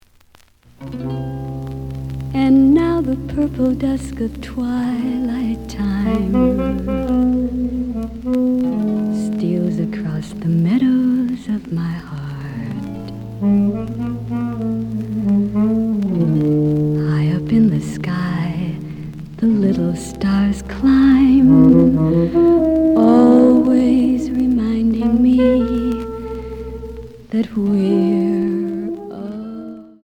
試聴は実際のレコードから録音しています。
●Genre: Rock / Pop
●Record Grading: EX- (盤に若干の歪み。多少の傷はあるが、おおむね良好。)